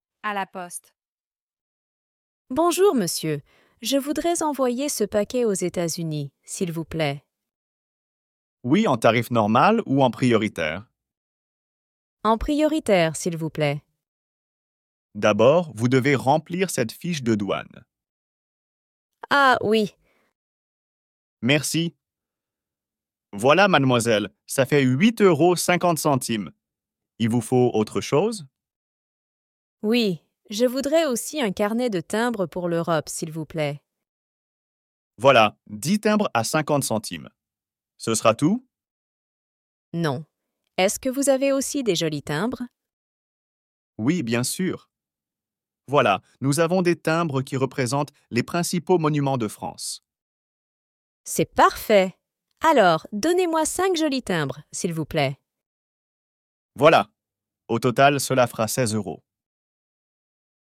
Dialogue FLE - à la poste
Dialogue-FLE-a-la-poste.mp3